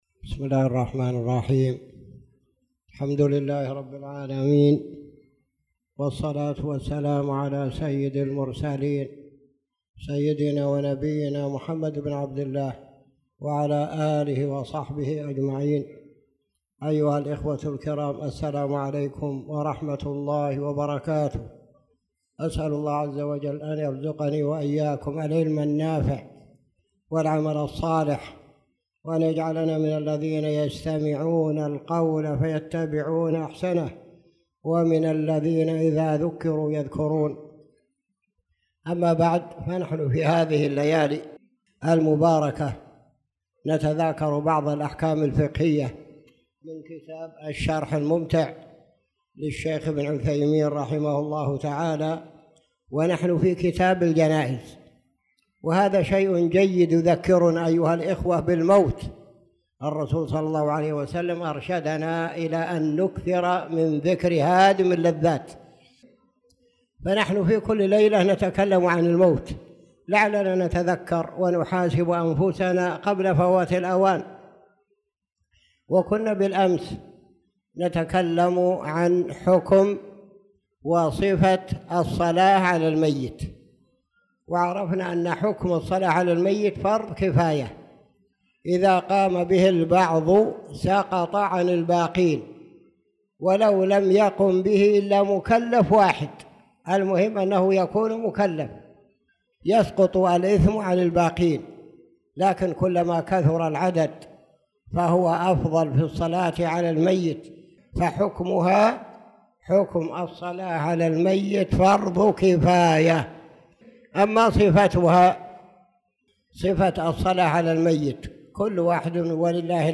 تاريخ النشر ١١ ذو القعدة ١٤٣٧ هـ المكان: المسجد الحرام الشيخ